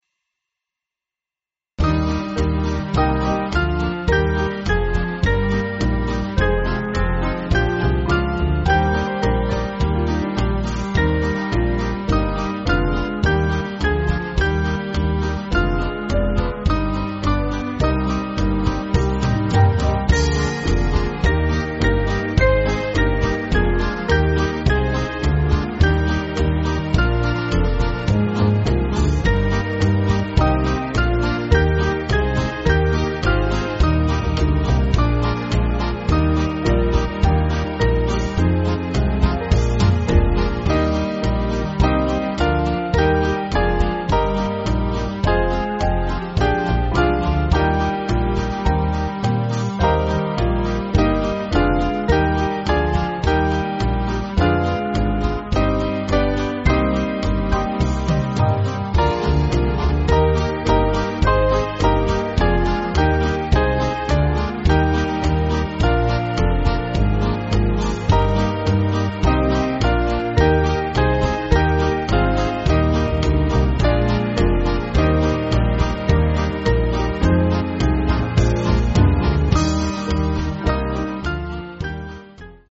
Small Band
(CM)   4/Eb